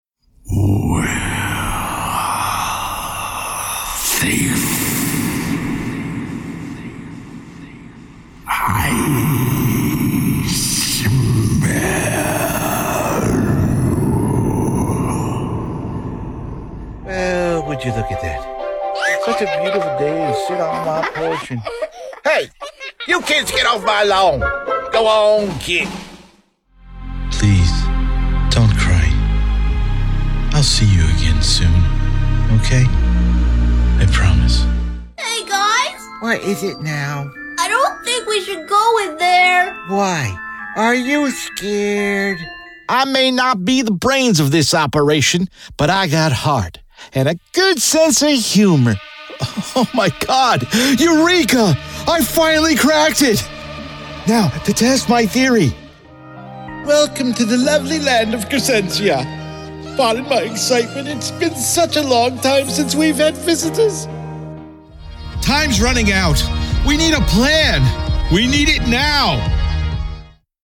Voice Actor from NJ, Aston Origin Microphone, FL Studio, PreSonus
Character Demo